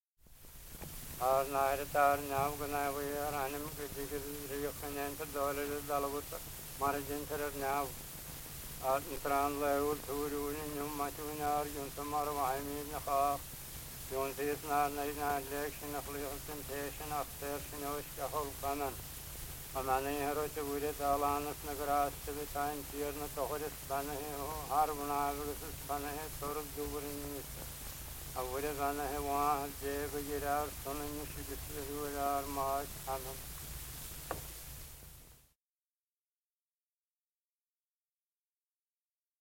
Commentary: This item, a recitation of the Lord's Prayer and the Ave Maria, was recorded primarily for phonetic purposes. There are five examples of each of these prayers in the Doegen collection.